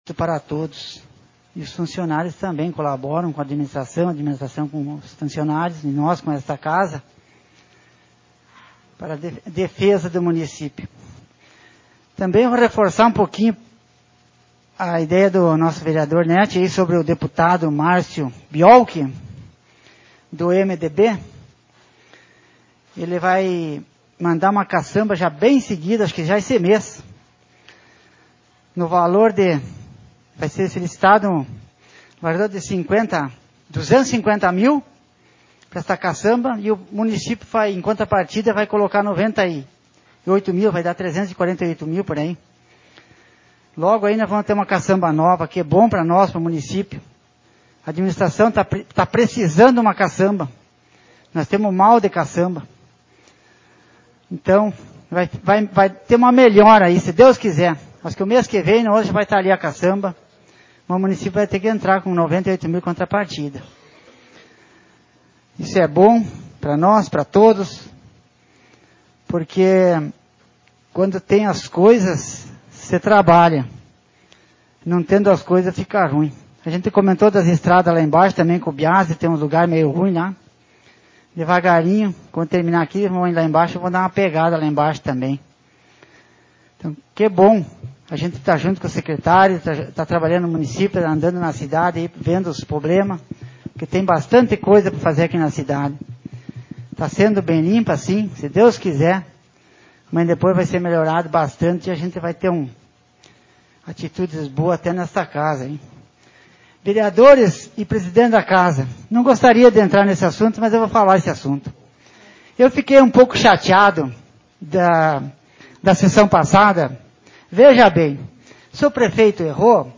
Sessão Ordinária 30/2021